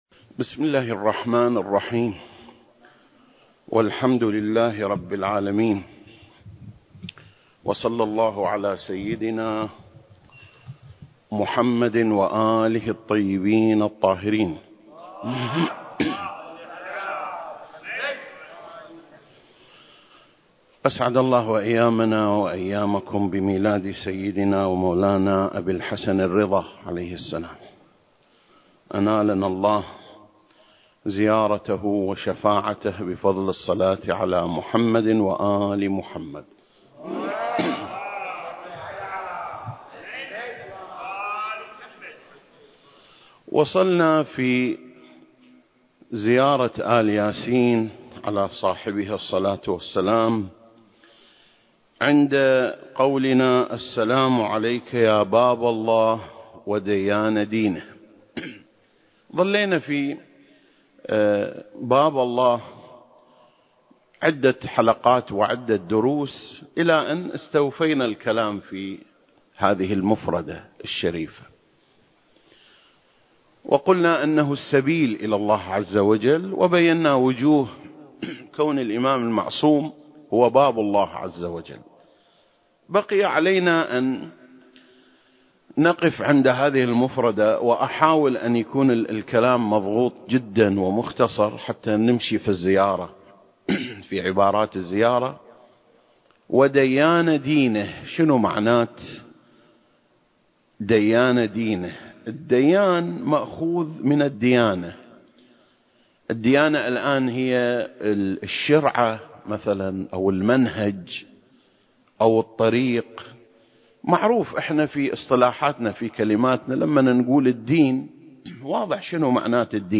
سلسلة: شرح زيارة آل ياسين (37) - ديان دينه (1) المكان: مسجد مقامس - الكويت التاريخ: 2021